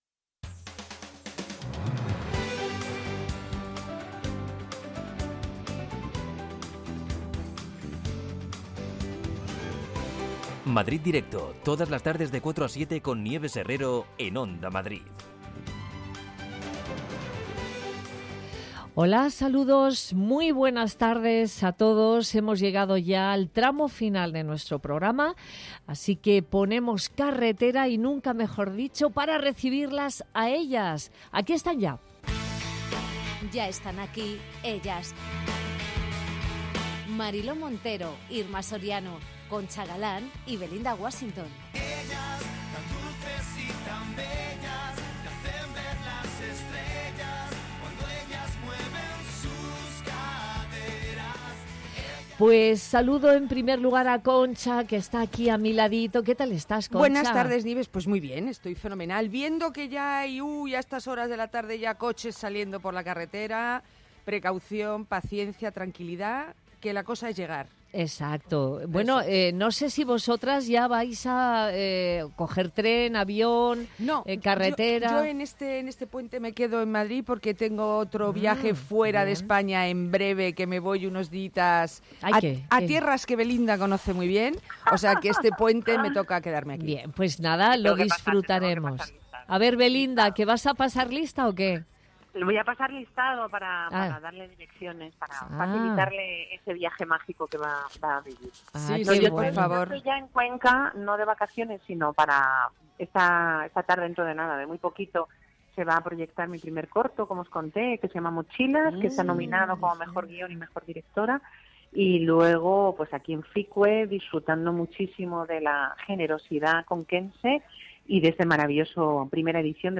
Nieves Herrero se pone al frente de un equipo de periodistas y colaboradores para tomarle el pulso a las tardes. Tres horas de radio donde todo tiene cabida. La primera hora está dedicada al análisis de la actualidad en clave de tertulia.